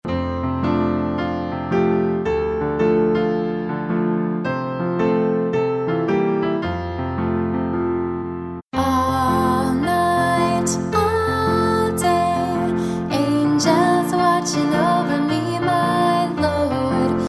Vocal Song Downloads